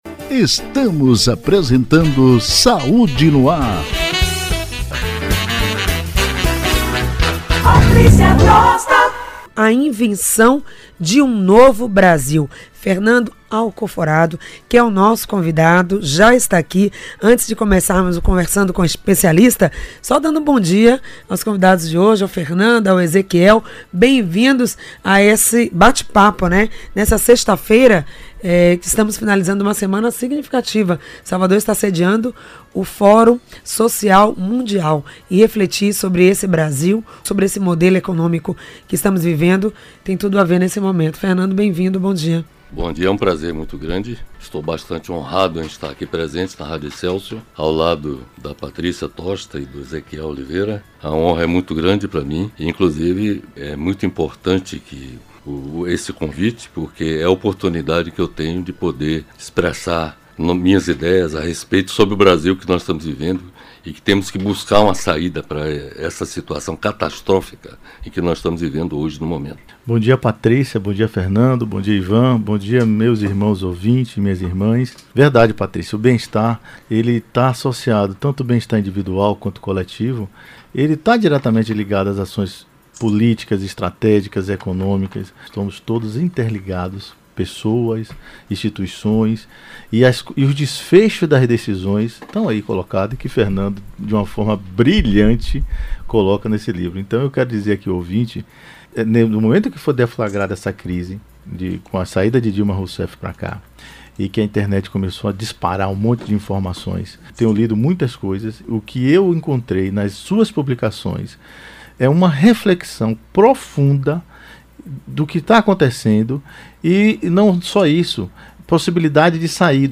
O programa Saúde no Ar é veiculado pela Rádio Excelsior da Bahia, AM840 e pela rádio on line Saúde no Ar .